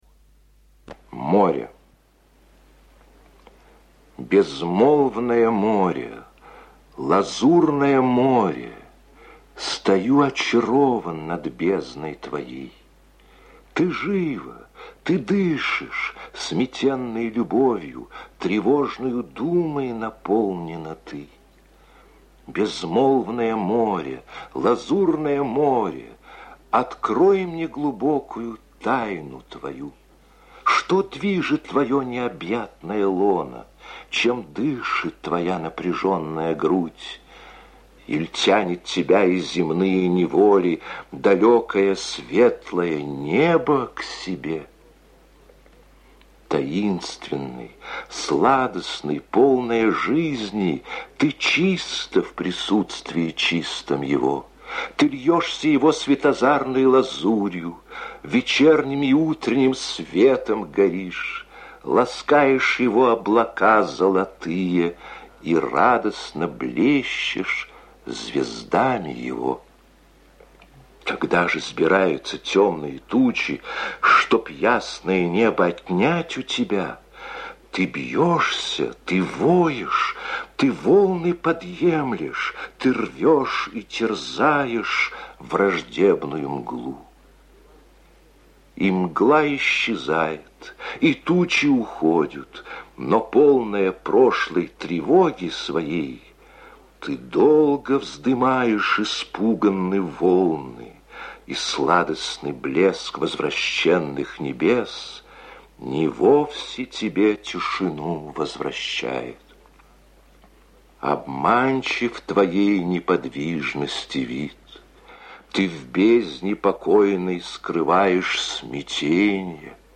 Прослушивание элегии «Море» в исполнении А. Консовского:
Выразительное чтение стихотворения.